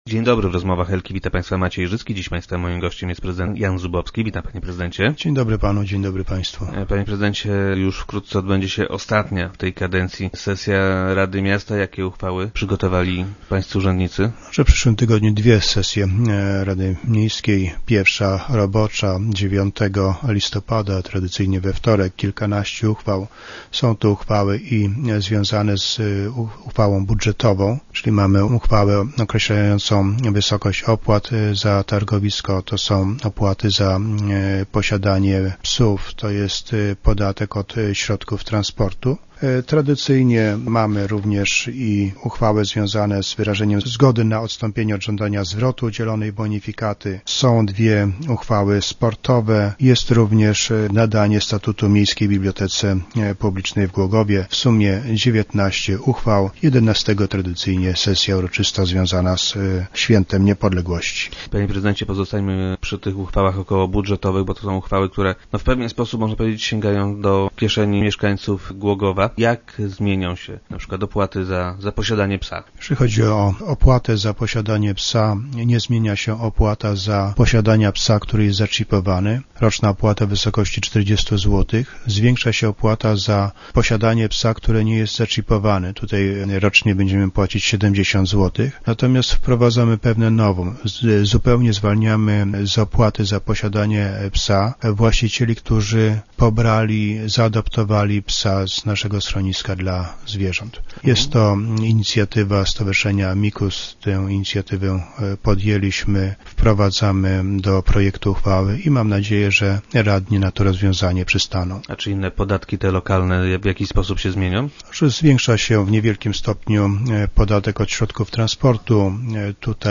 - To były owocne lata – mówi o swojej współpracy z radnymi prezydent Jan Zubowski, który był gościem Rozmów Elki.